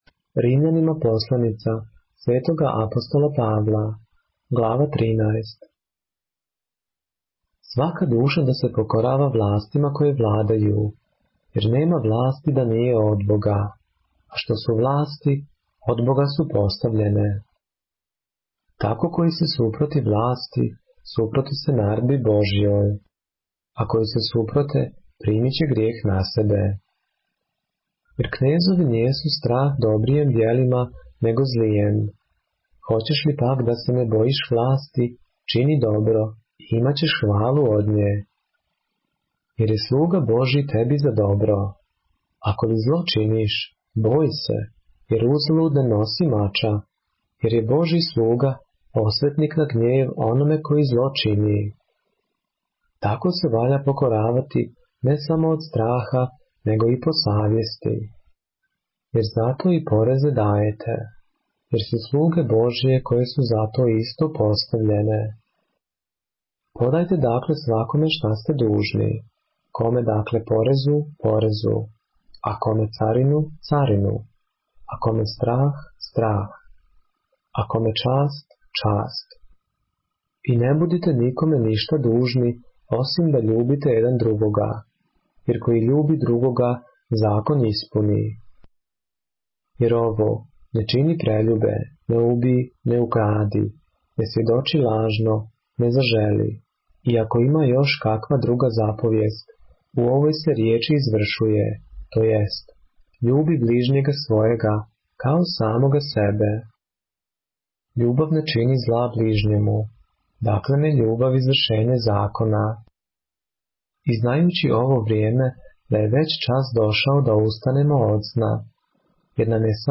поглавље српске Библије - са аудио нарације - Romans, chapter 13 of the Holy Bible in the Serbian language